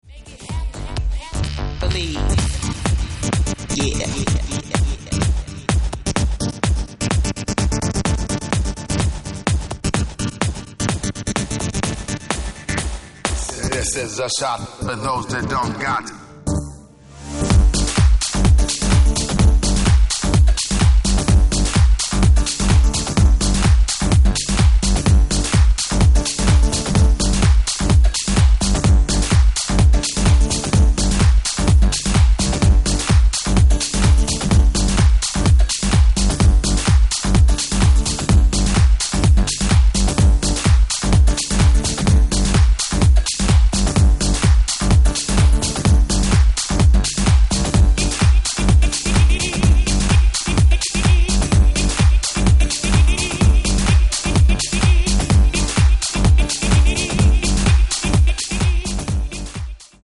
It’s an intense workout to satisfy all tech house lovers.